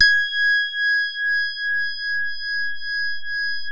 JAZZ SOFT#G5.wav